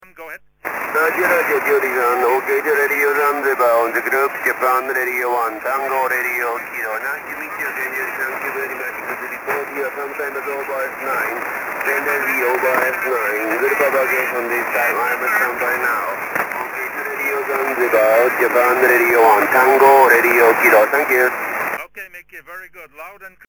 Jak posloucháte DXy v pásmu 80m?